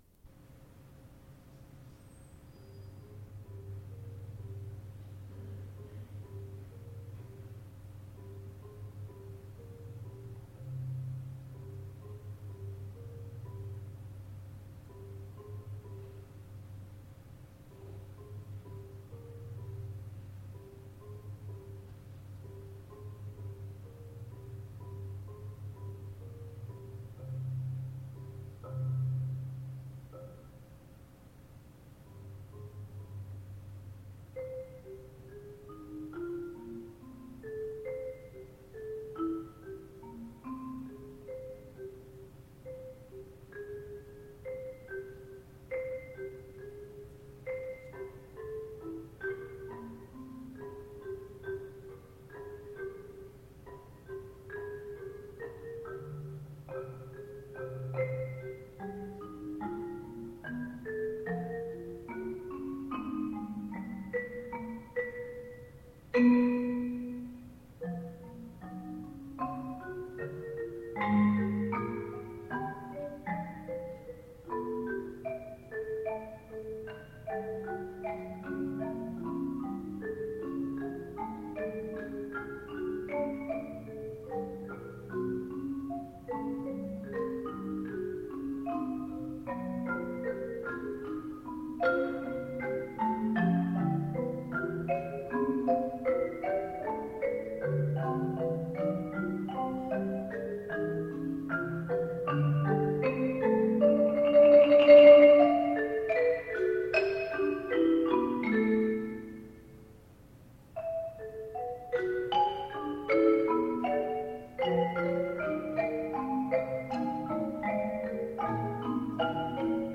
Genre: Percussion Ensemble
Percussion 4 (5-octave marimba, chimes, claves)
Percussion 8 (4 timpani, temple blocks, tam-tam)